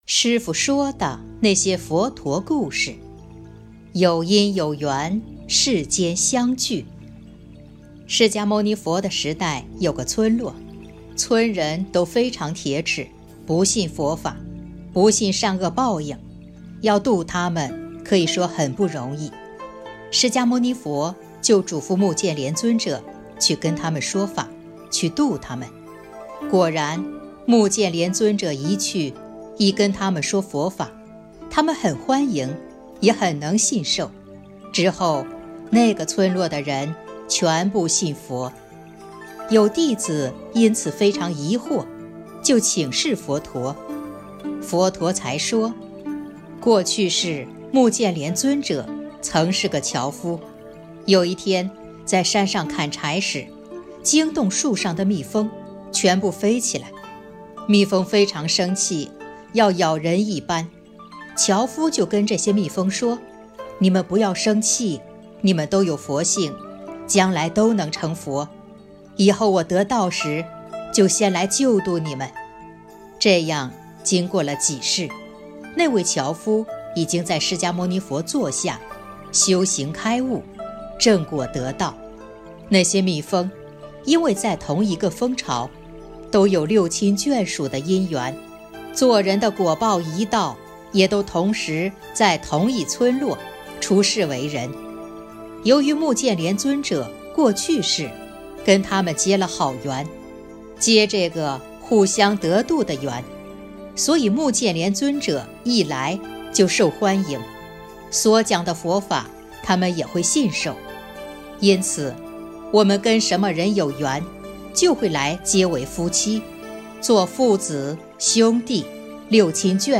音频：《结缘易度，有因有缘，世间相聚》师父说的那些佛陀故事！！2020年02月26日【师父原声音】